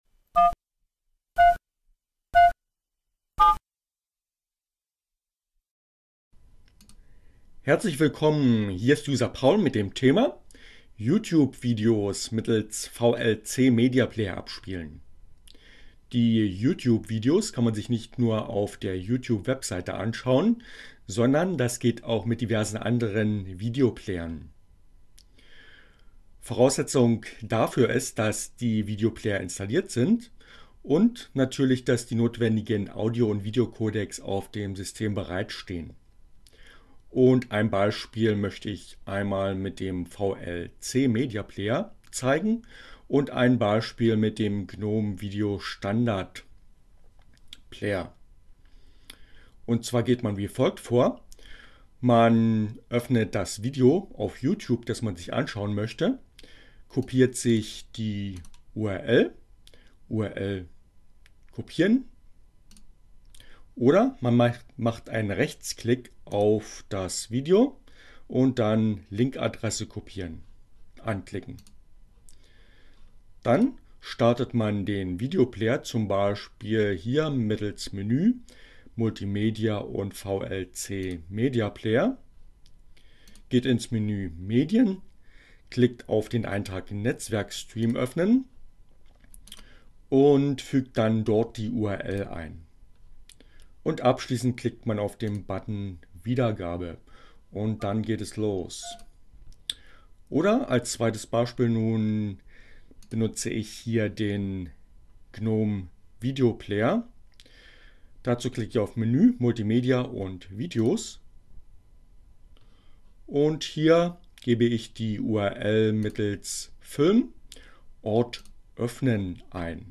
Tags: CC by, Linux, Neueinsteiger, ohne Musik, screencast, VLC, Totem